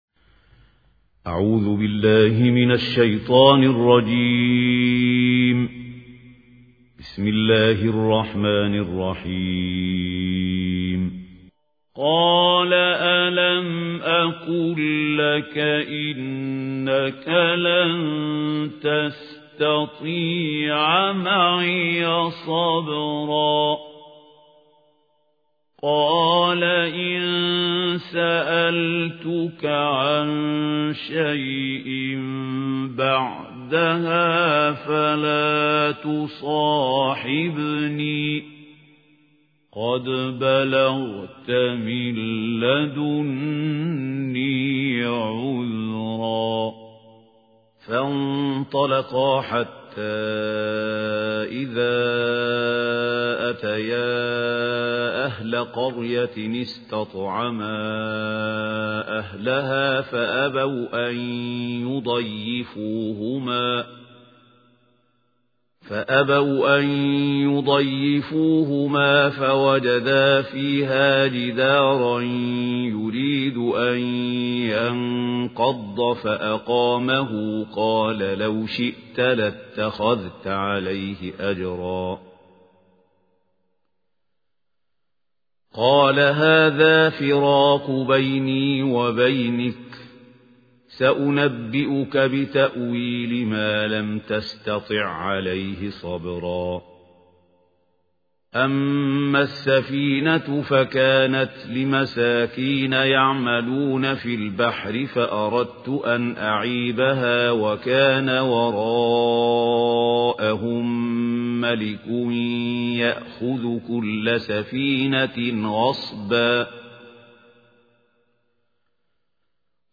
ترتیل جزء شانزدهم با صدای استاد محمود خلیل الحصری